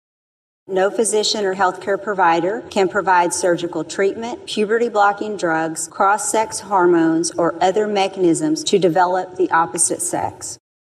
She tells her colleagues her proposal seeks to establish guidelines for student participation in athletic contests organized by sex:
Audio provided by the Missouri Senate